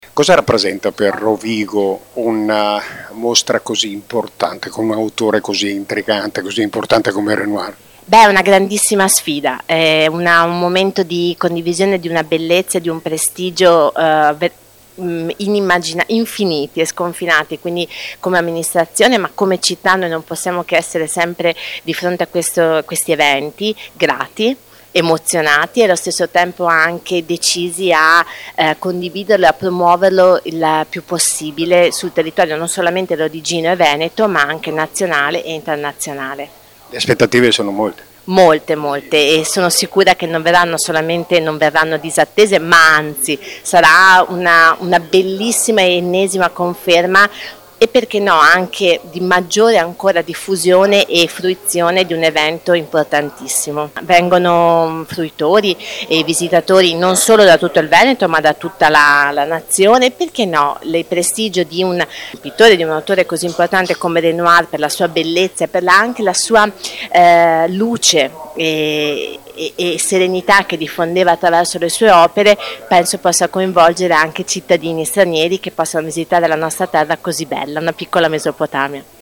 Benetta Bagatin, Assessore alla cultura del Comune di Rovigo
Benedetta-Bagatin-assessore-alla-cultura-del-comune-di-Rovigo-su-Renoir.mp3